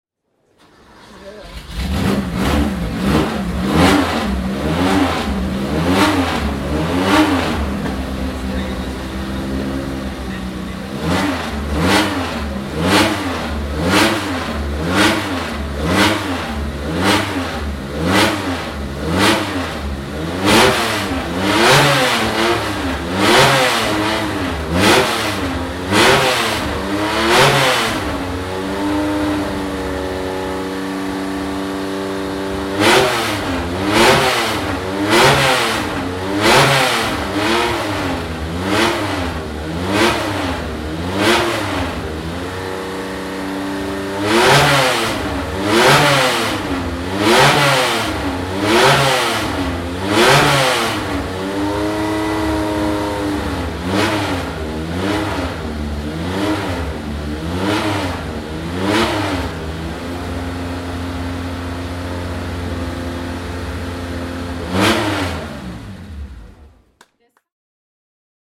Porsche 904/8 Carrera GTS (1964) - Starten und Hochdrehen an der Porsche Sound-Nacht 2013